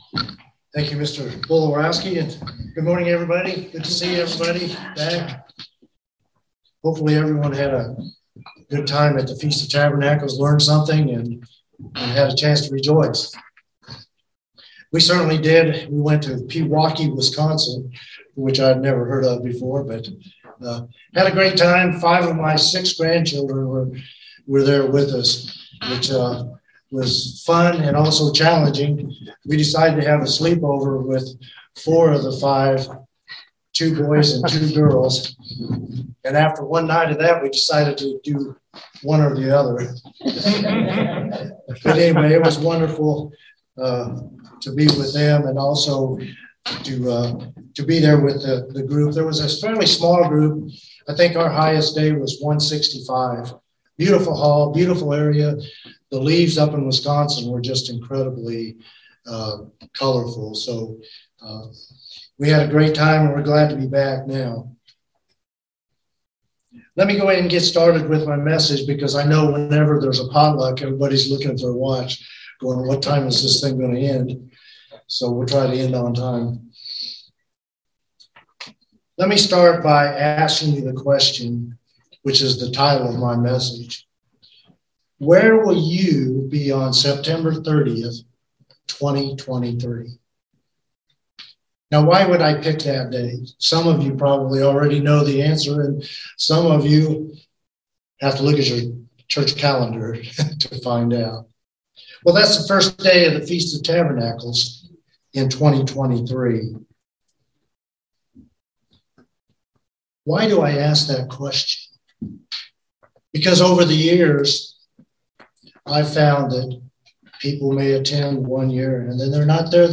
Sermons
Given in Central Georgia Columbus, GA